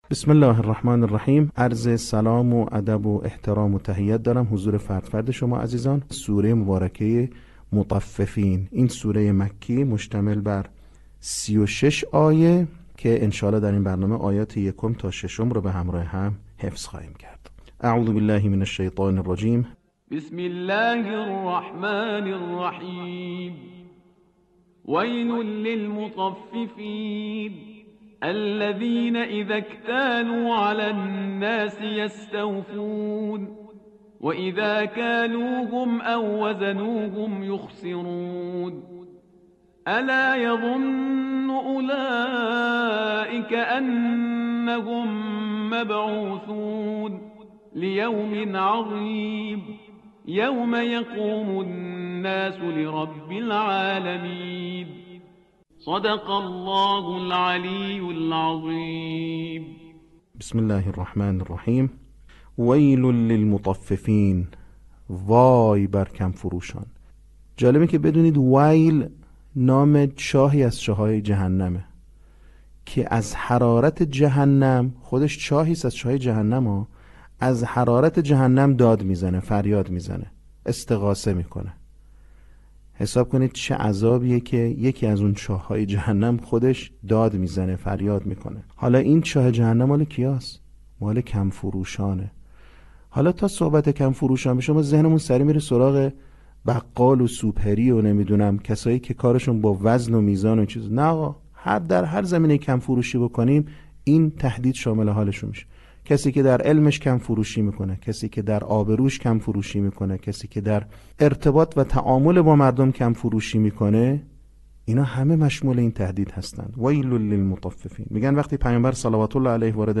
صوت | آموزش حفظ سوره مطففین